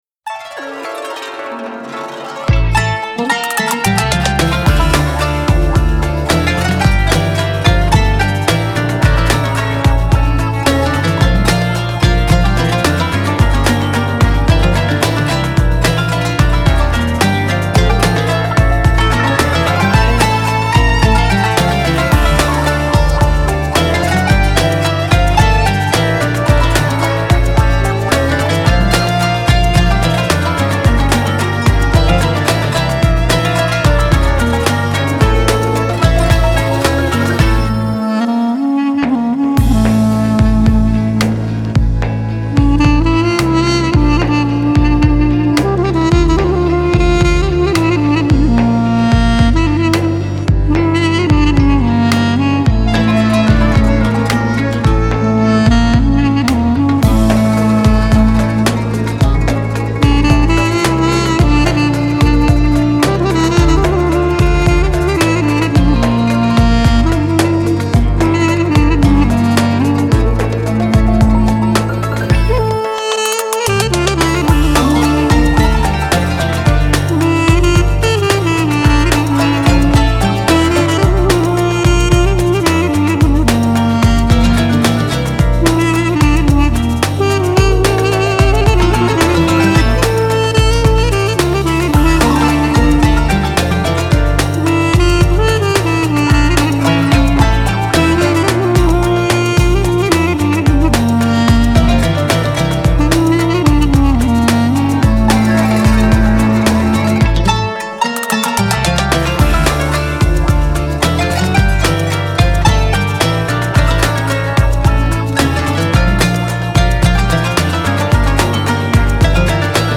امید‌بخش
بومی و محلی